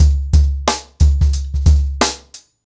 TrackBack-90BPM.71.wav